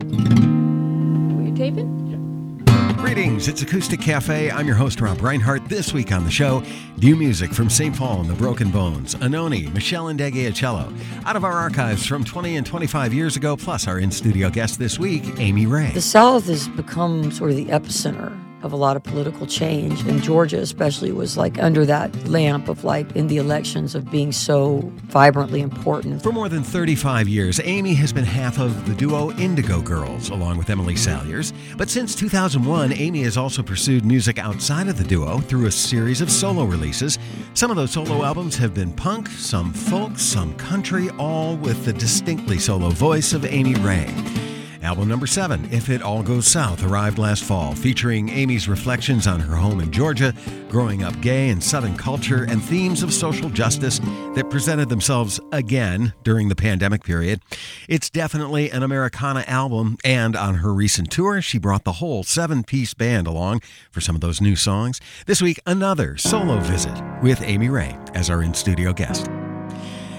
(webstream capture)